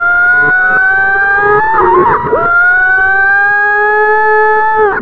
ALARMREV  -L.wav